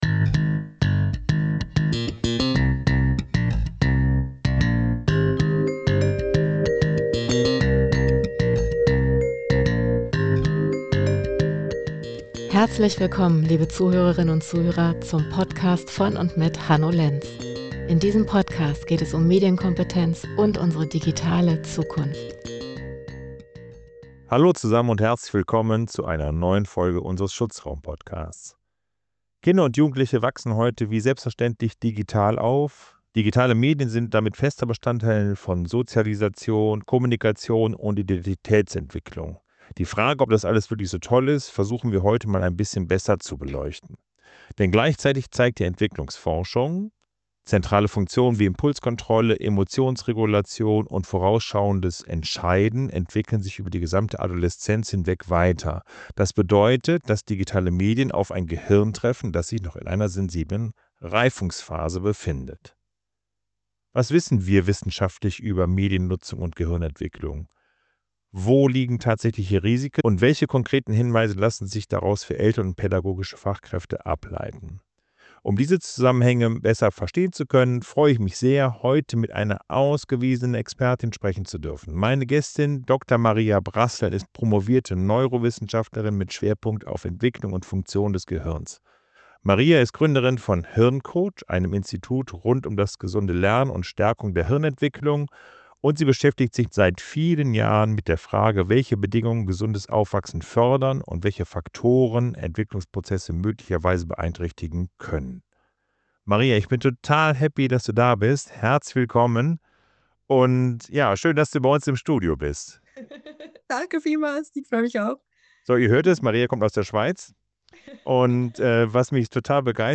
In dieser Folge sprechen wir mit einer Neurowissenschaftlerin über die Grundlagen der Gehirnentwicklung und darüber, wie intensive Mediennutzung wirken kann. Wir ordnen wissenschaftliche Erkenntnisse ein, unterscheiden zwischen belegten Risiken und offenen Fragen – und leiten konkrete Impulse für Eltern und pädagogische Fachkräfte ab.